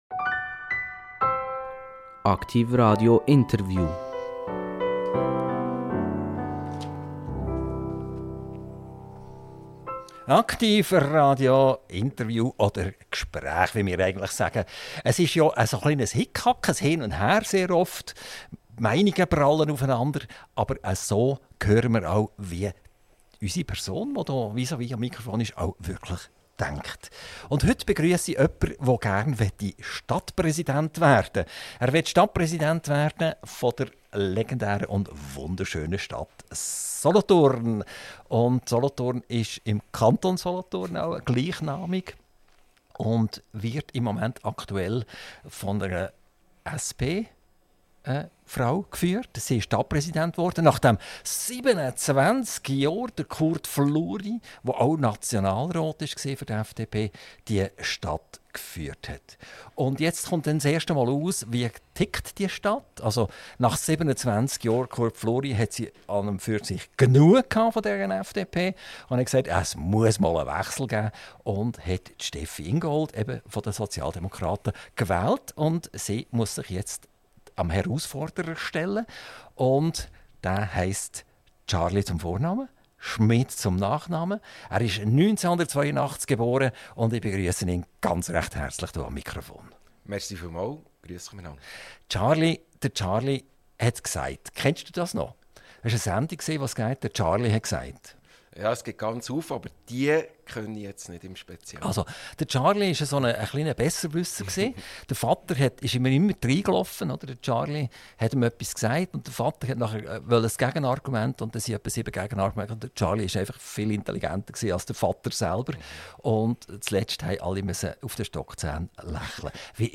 📢 Exklusives Live-Interview bei Aktiv Radio!